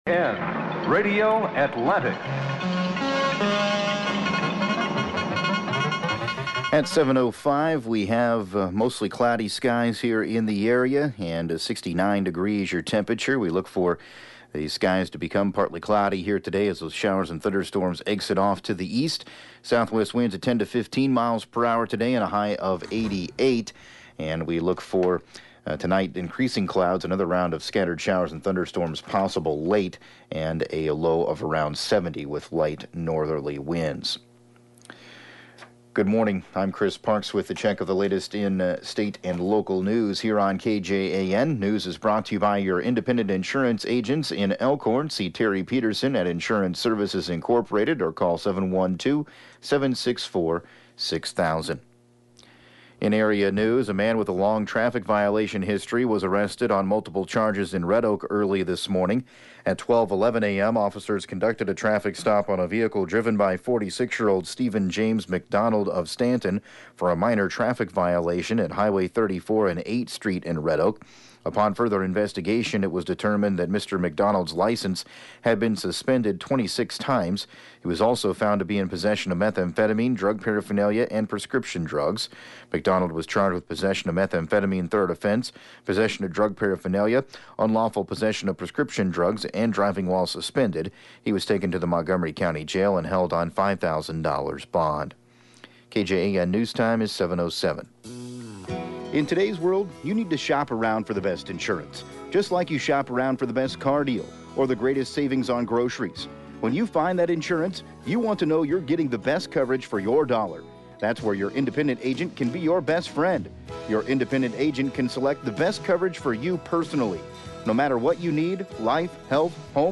7AM Newscast 08/20/2019